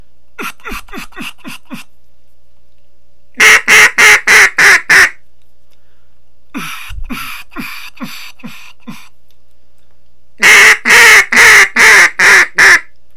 Duck Calling - Feeding Chuckle - Page 2
Most calling is done with only a short initial burst of the larynx at the start of a note
To do so results in a rough, gravely sound …and usually a sore throat at the end of the day.